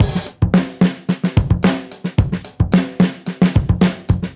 Index of /musicradar/dusty-funk-samples/Beats/110bpm
DF_BeatC_110-01.wav